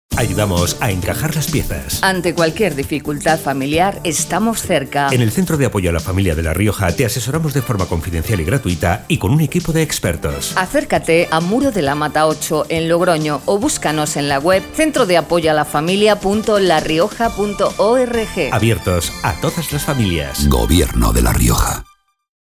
Elementos de campaña Cuñas radiofónicas Cuña genérica.